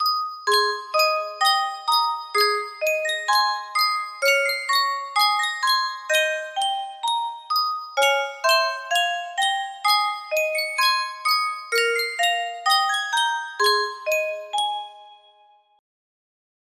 Sankyo Spieluhr - Im Gazen Vaterland TYQ music box melody
Full range 60